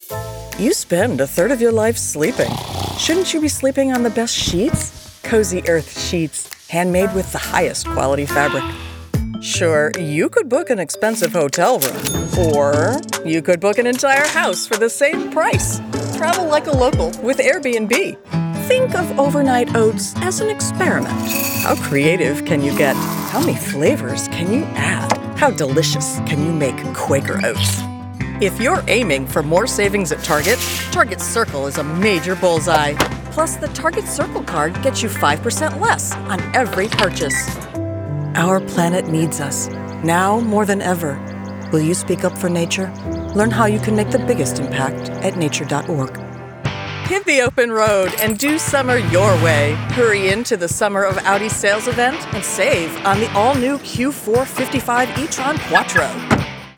Middle Aged
Commercial
Whether you’re looking for a warm, approachable tone for a commercial, a polished, authoritative voice for corporate narration, or a nuanced character for an audio book, I can provide a voice right for your project.